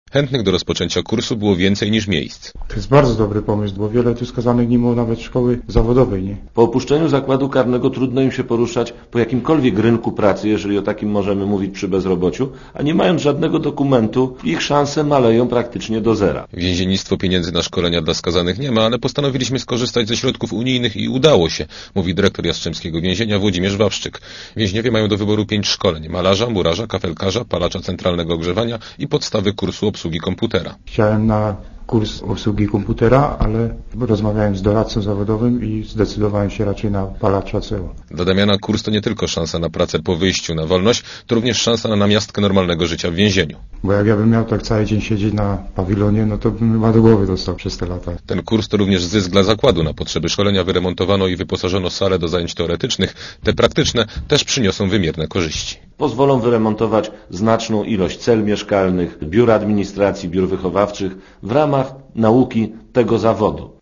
| Komentarz audio | | --- | Program szkoleń dla więźniów pod nazwą „Start” jest współfinansowany z funduszy Phare.